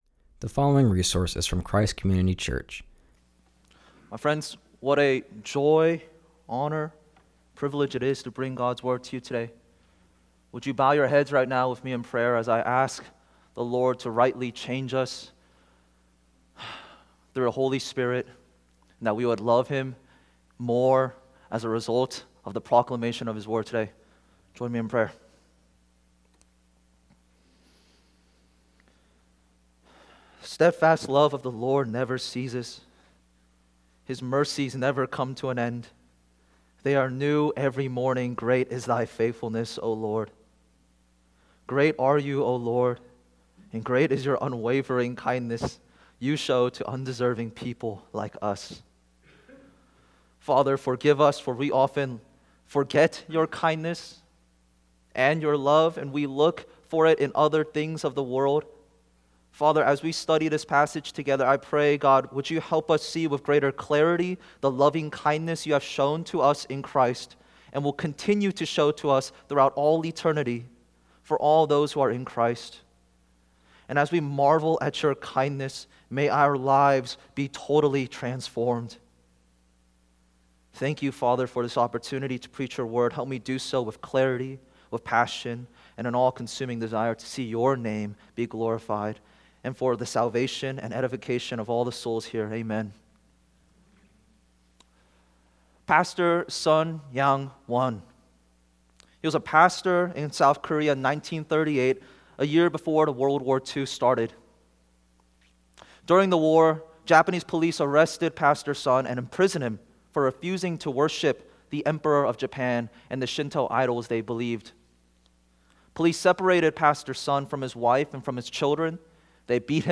preaches from 2 Samuel 9:1-13.